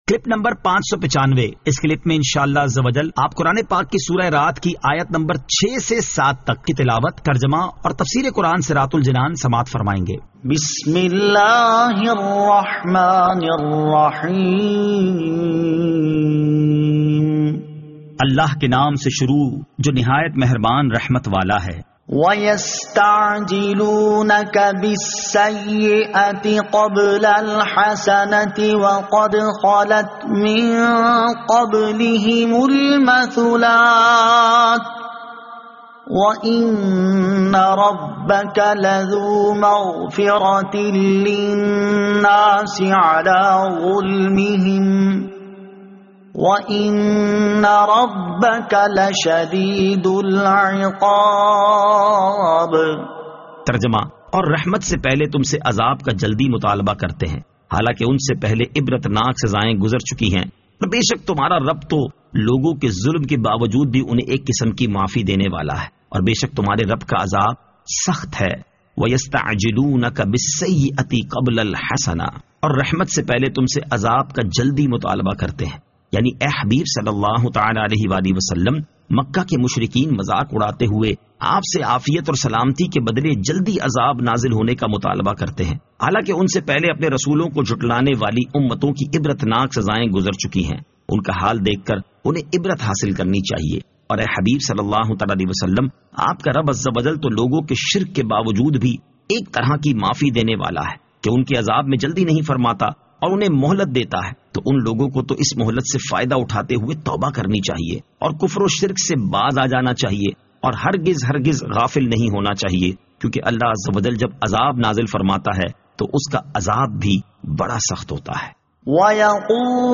Surah Ar-Rad Ayat 06 To 07 Tilawat , Tarjama , Tafseer